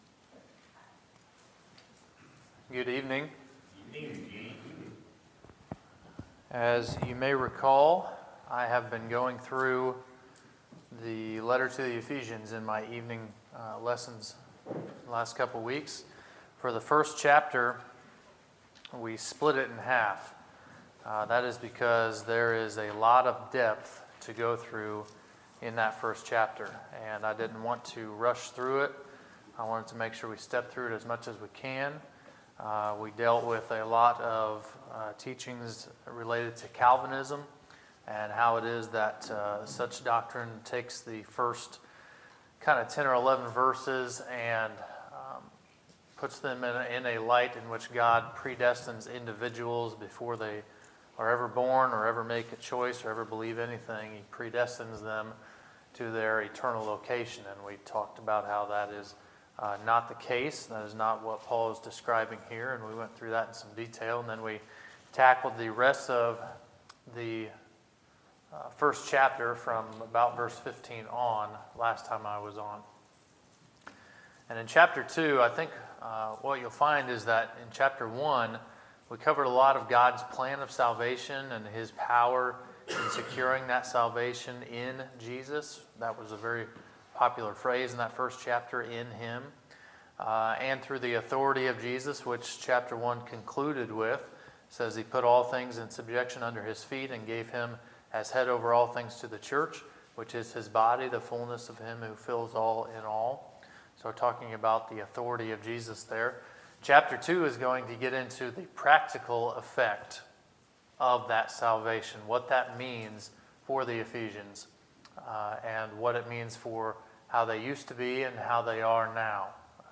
Sermons, February 19, 2017